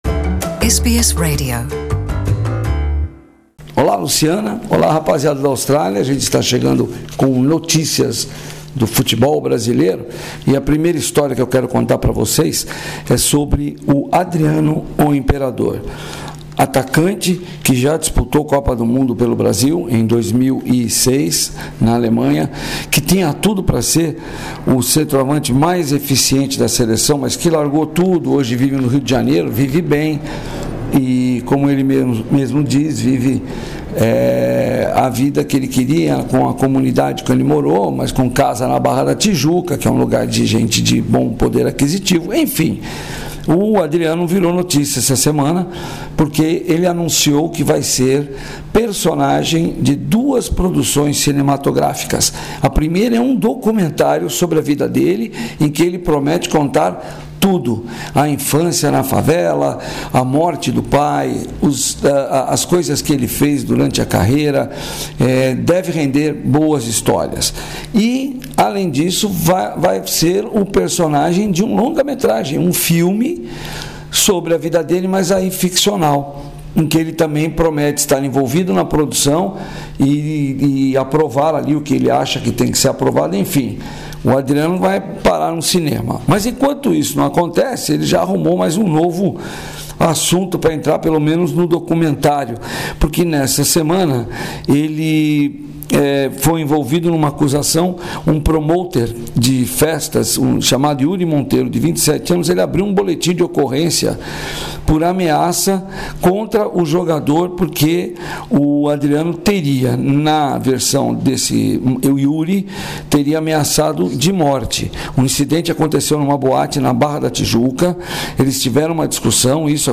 A produção deve começar em 2021. Ainda neste boletim esportivo, Neymar Junior promete se apresentar ao PSG nesta segunda-feira.